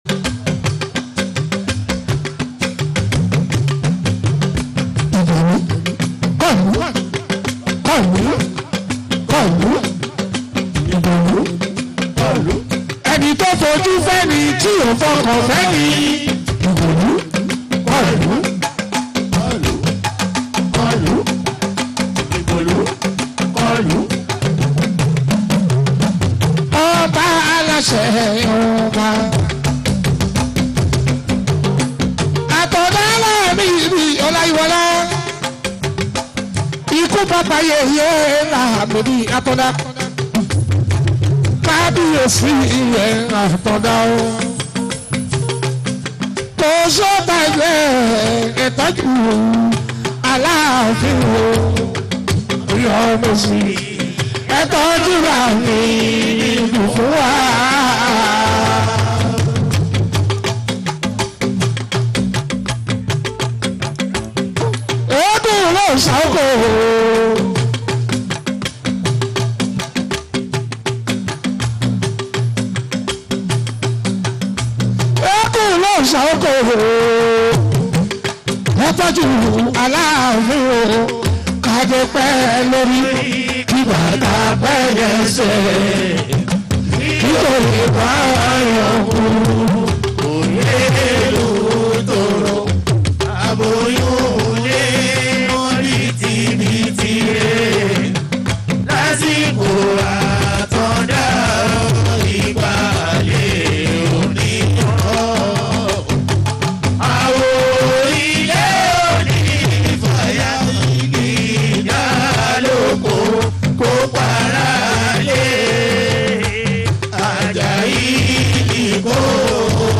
Fuji
Nigerian Yoruba Fuji track